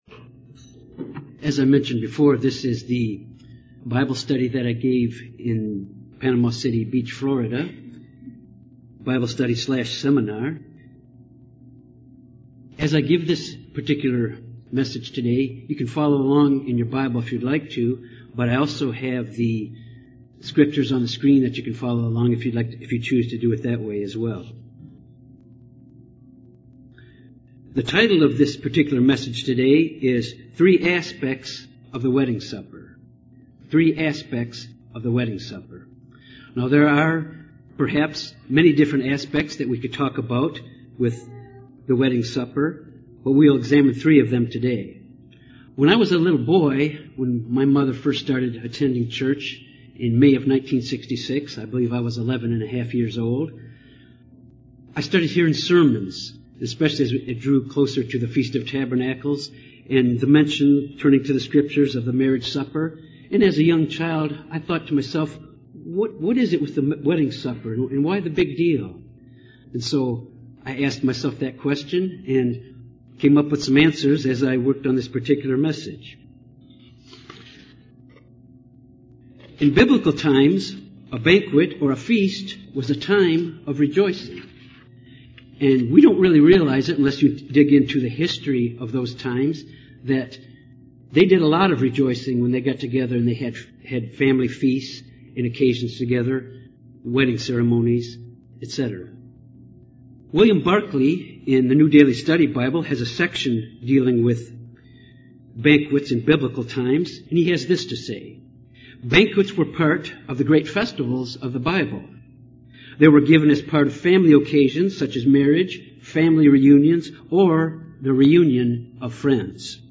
Given in Little Rock, AR
Sermon Handout UCG Sermon Studying the bible?